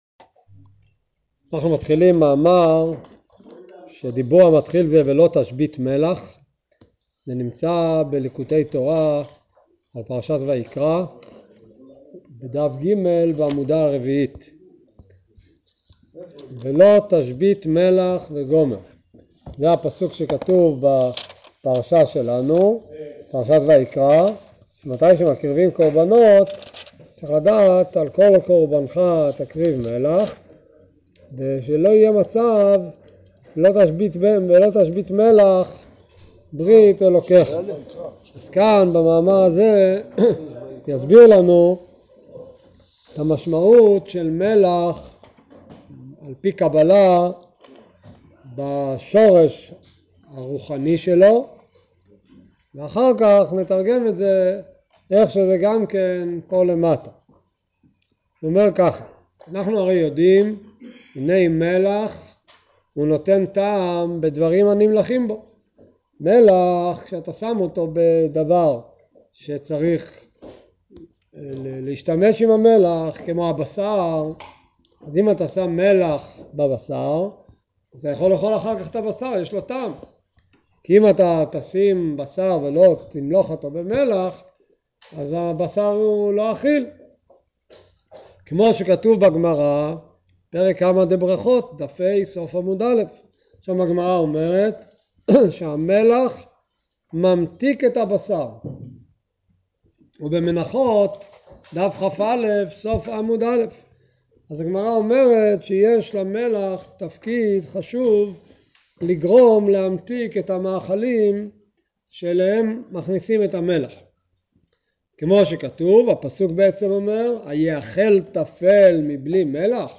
שיעורי תניא | חומש עם רש״י | שיעורי תורה